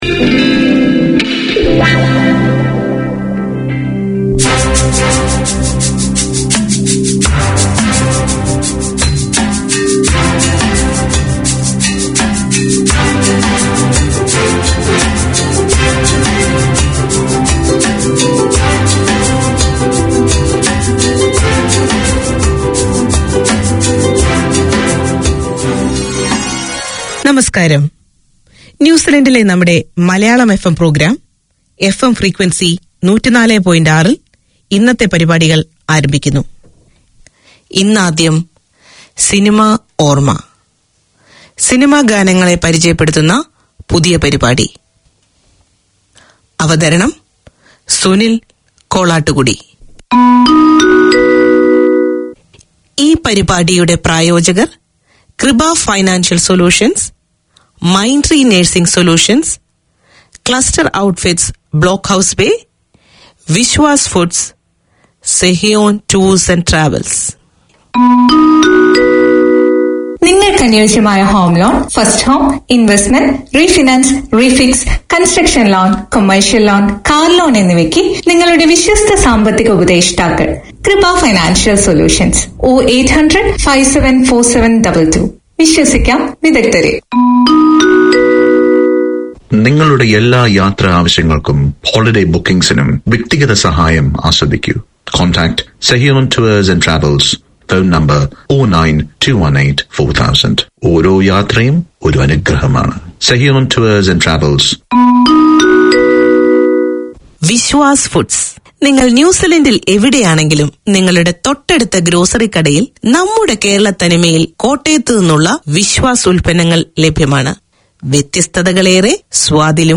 Manukau Indian FM is a registered charity whose vision is to connect communities with the Hindu, Sanatan, and Indian culture. The programme showcases the history, traditions and festivals of India and Fiji through storytelling and music, including rare Fiji Indian songs.